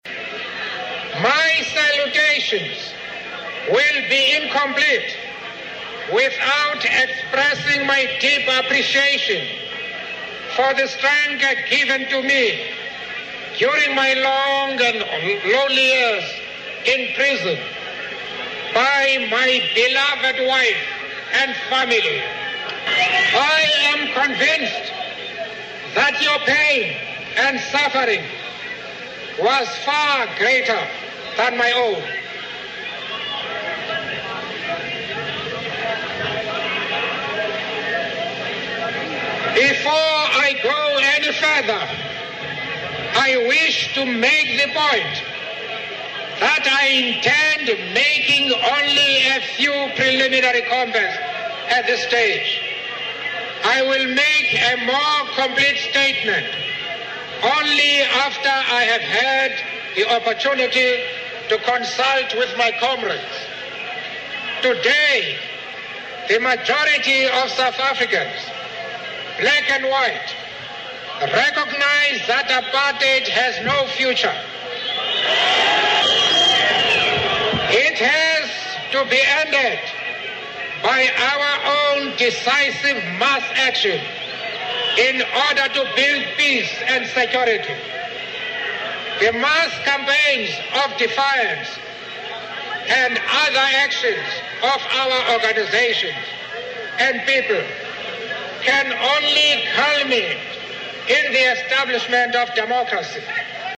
名人励志英语演讲 第32期:为理想我愿献出生命(6) 听力文件下载—在线英语听力室